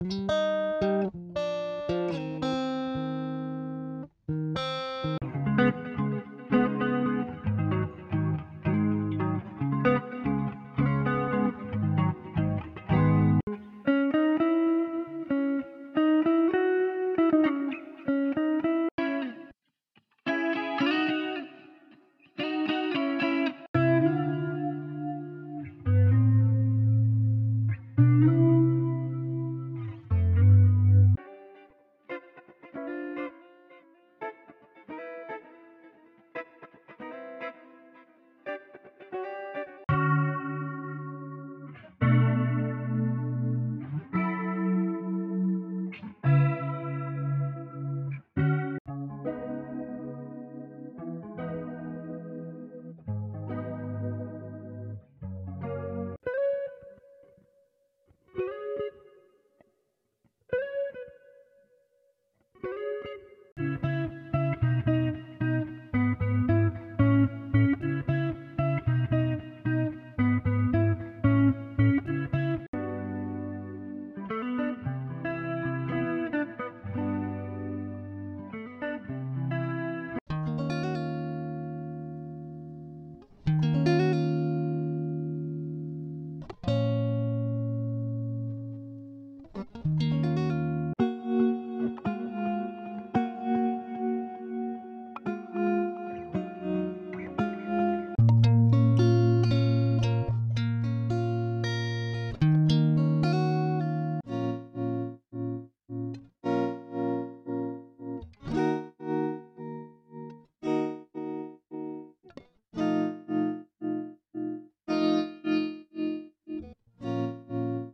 – Over 550+ Guitar Loops